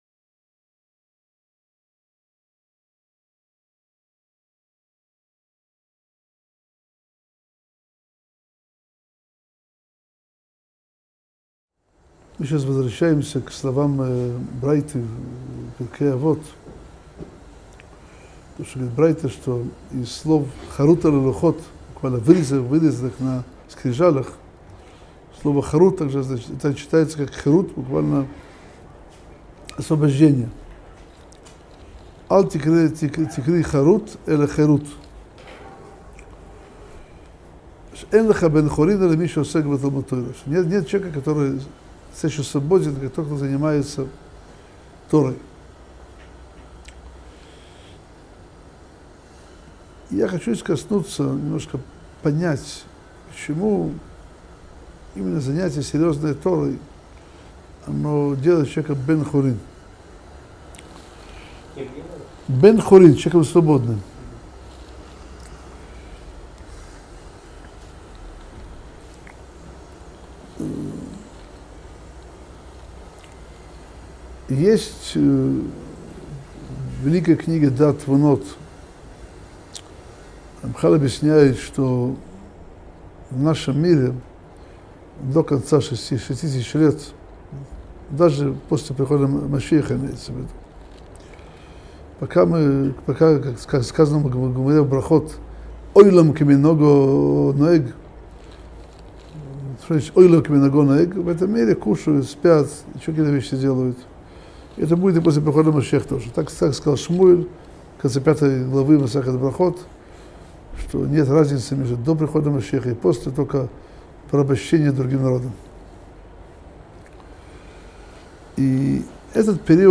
Пути приобретения Торы - Урок 68 - Как не быть рабом желаний? - Сайт о Торе, иудаизме и евреях